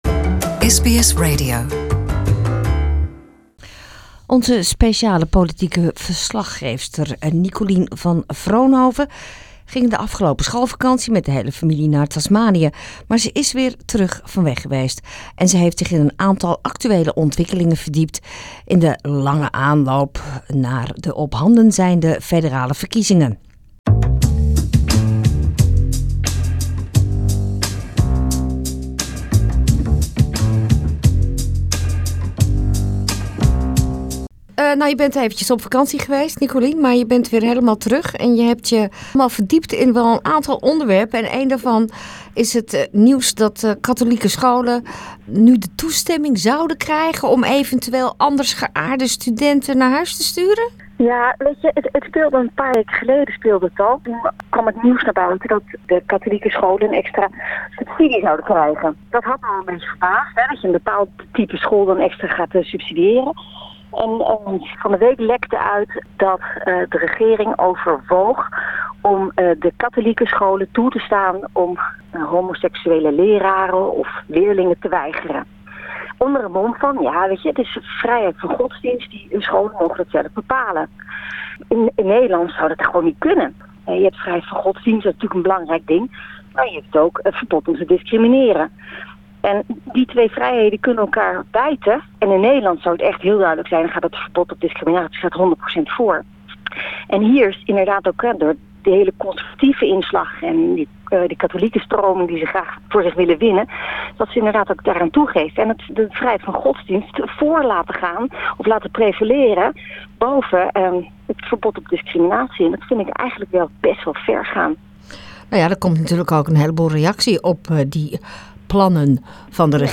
Special reporter for Political Affairs, Dutch ex-parliamentarian Nicolien van Vroonhoven, sheds her light on freedom of religion, migrants living in the countryside and tax cuts for small businesses.